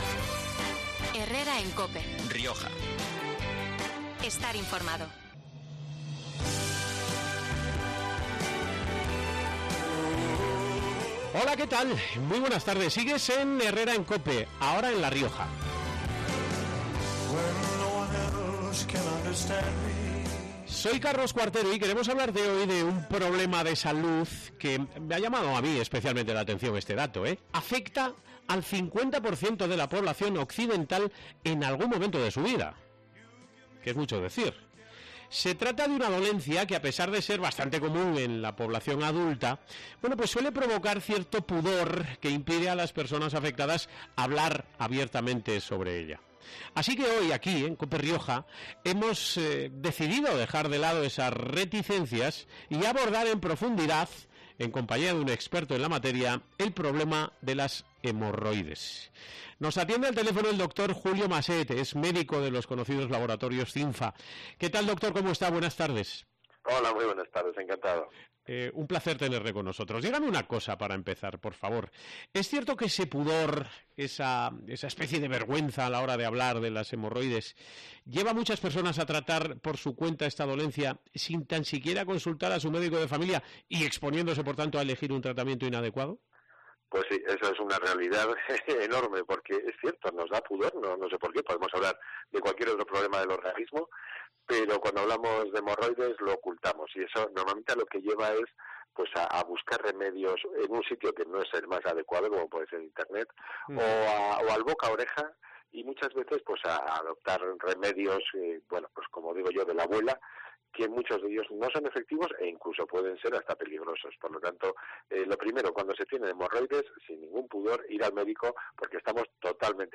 Logroño